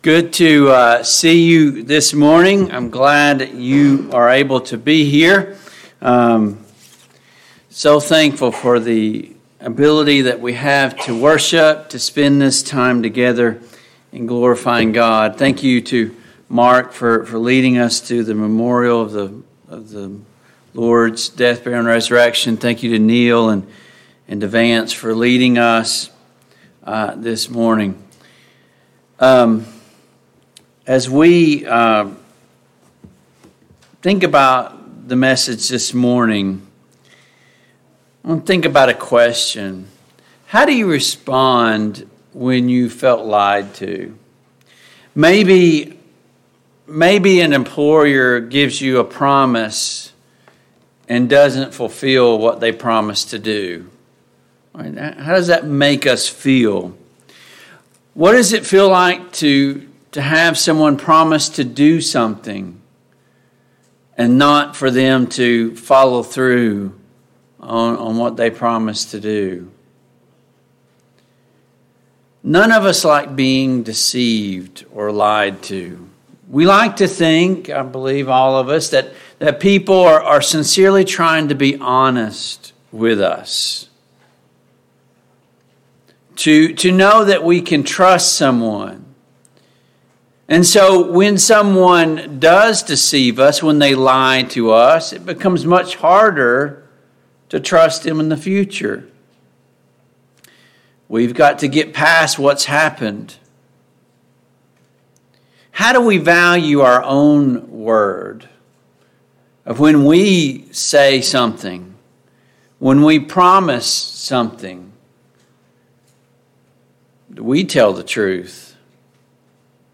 James 5:1-6 Service Type: AM Worship Download Files Notes Topics: The Brevity of Life « 10.